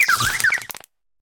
Cri de Têtampoule dans Pokémon HOME.